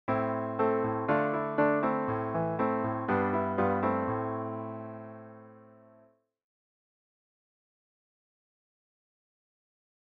To give you an idea of the effect we’re talking about, here are some simple short melodic fragments, first given in a major key, and then in a minor key equivalent:
Am  Dm  Am  G  Am (
The minor mode gives the music a darker mood, with potential for a bit more edge. These are just midi files that I’ve posted here, so you’ll have to use your imagination and your own instrumentation to bring them to life.